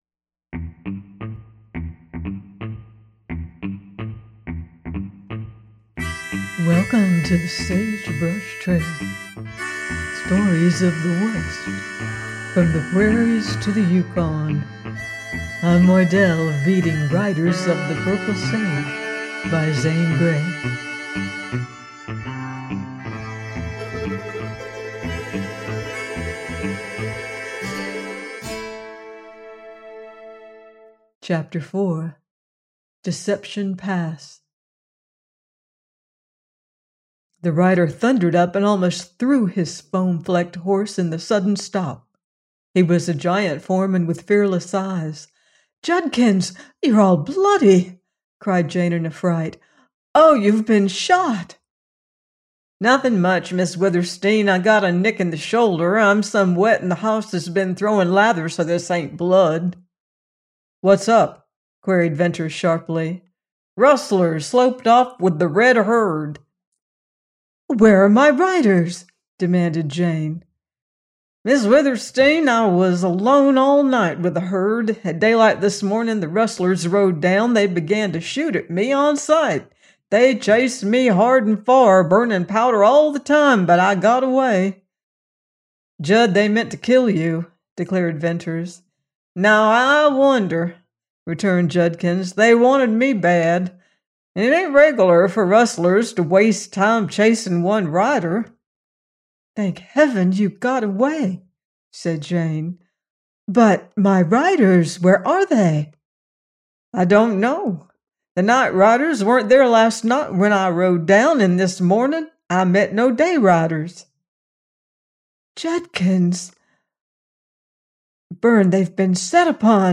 Riders of the Purple Sage – Ch 4 : by Zane Grey - audiobook